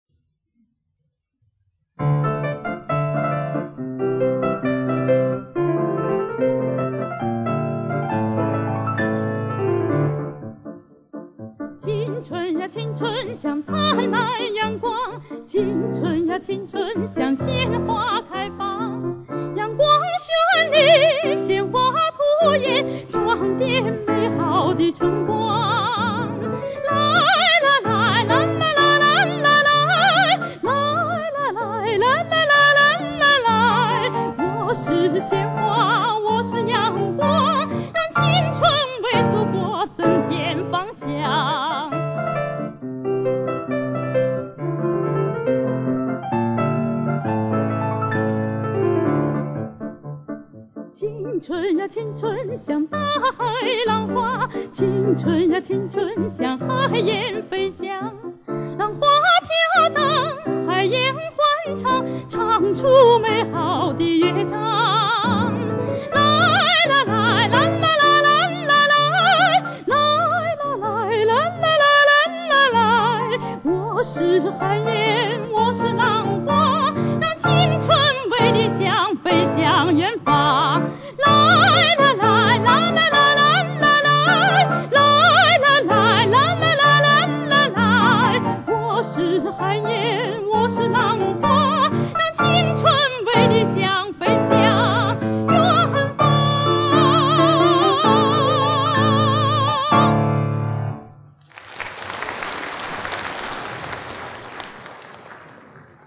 钢琴
女声独唱